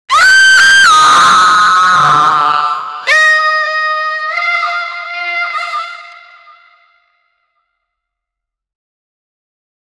Royalty-free soul sound effects